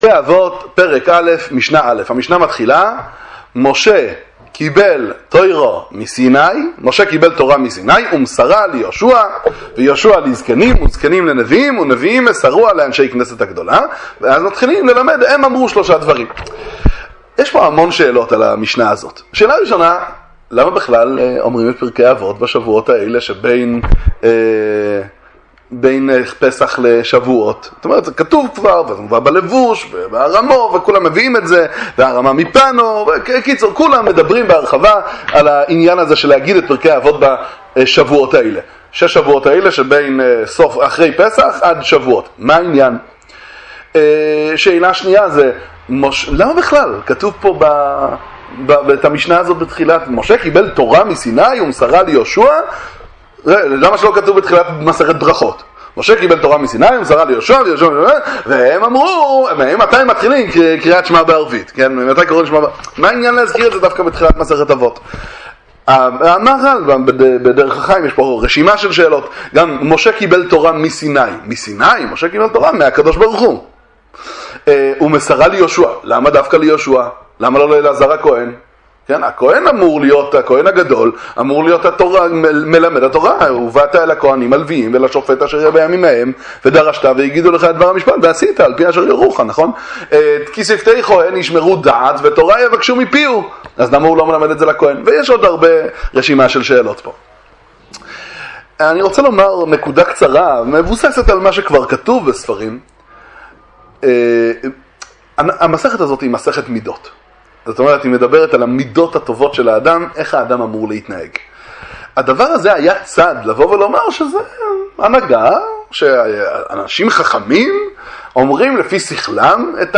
דבר תורה קצר על פרקי אבות – פרק א' משנה א' – מידות טובות כתנאי לקבל תורה